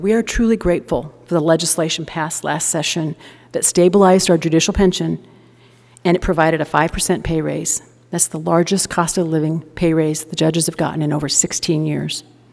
Christensen delivered the annual “Condition of the Judiciary” address to legislators this week.